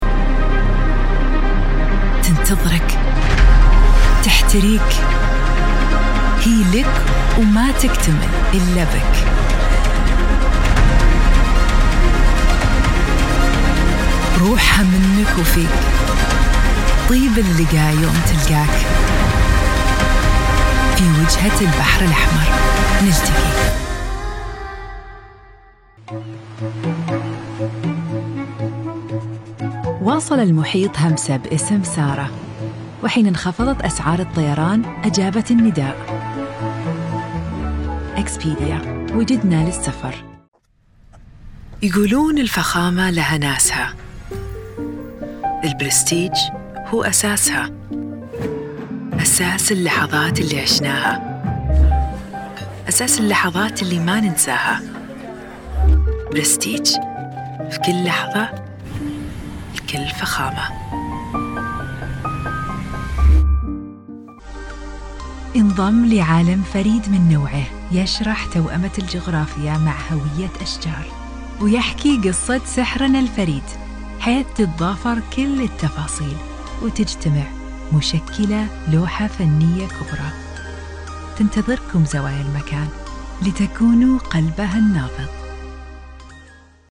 Tief, Natürlich, Unverwechselbar
Unternehmensvideo